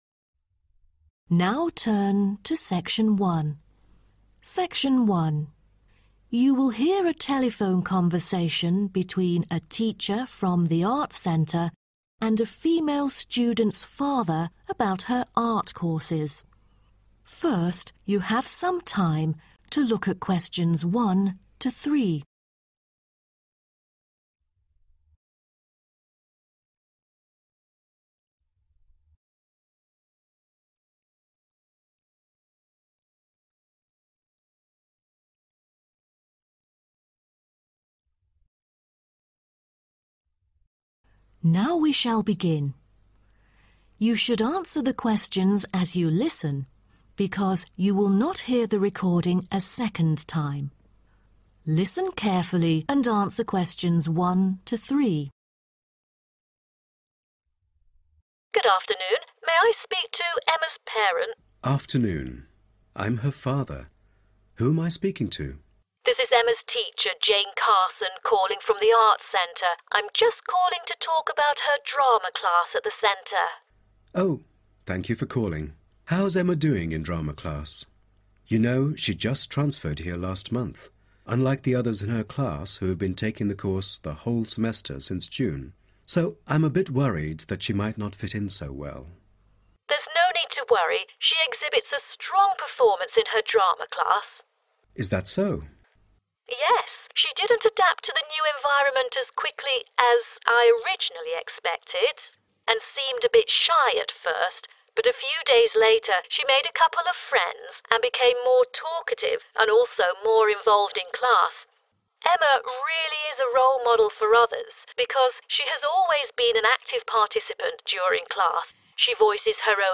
This section typically tests your ability to follow a conversation or monologue related to drama classes, their structure, content, and participant experiences.
What is the drama teacher telephoning about?